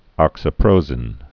(ŏksə-prōzĭn)